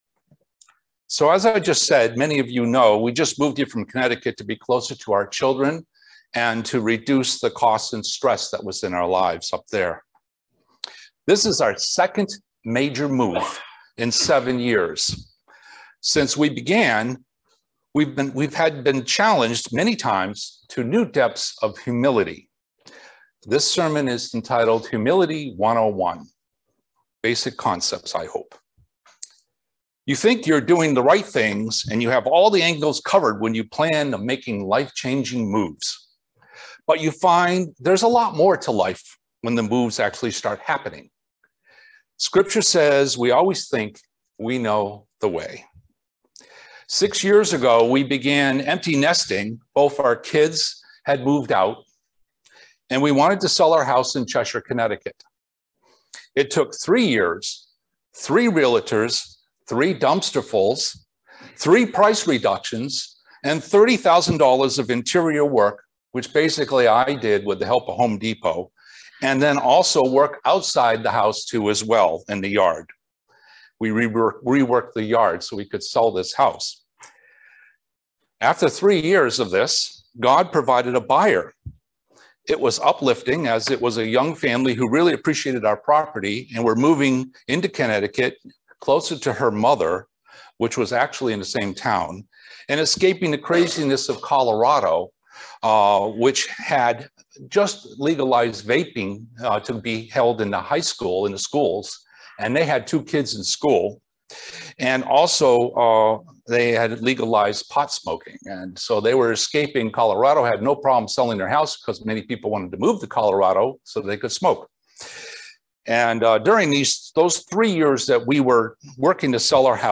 Join us for this excellent sermon on the subject of humility. What does the scripture say about humility?